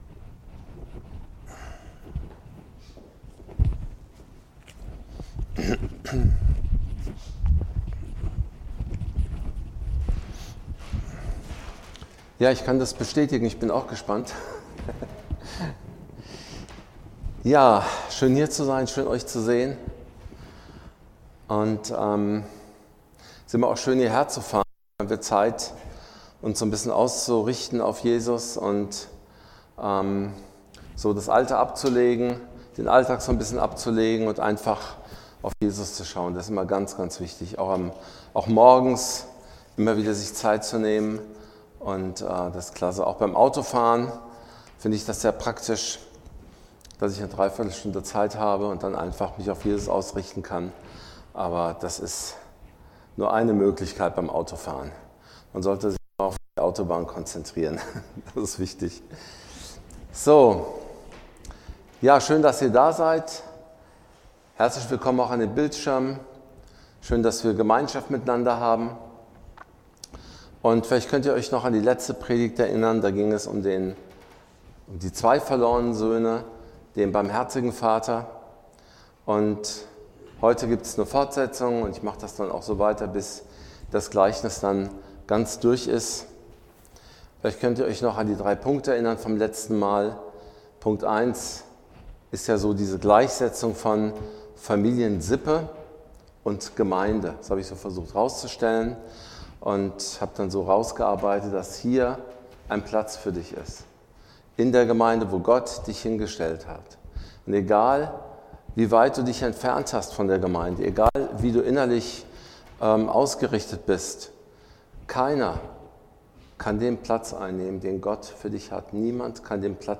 Passage: Lukas 15 Dienstart: Predigt Lasst uns die Wunder Gottes und sein Wort nicht vergessen.